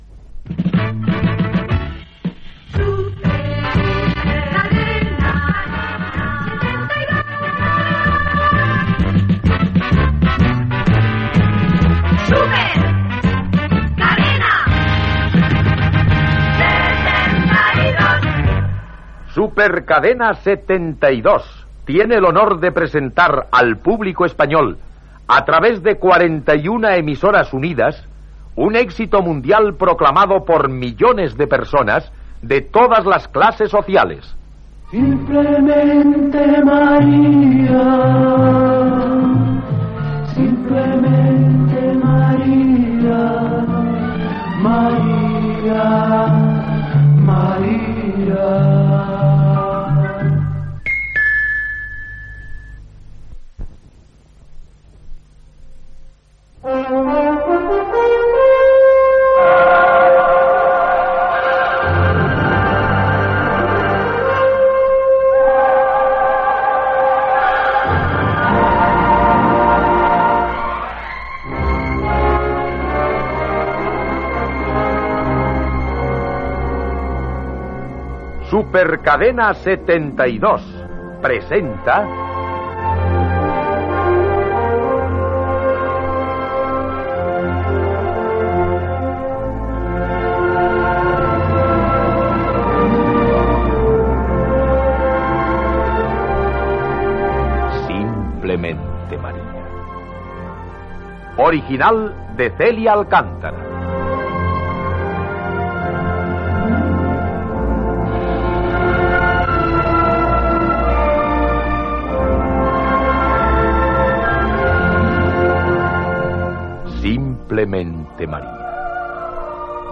Ficció
Radionovel·la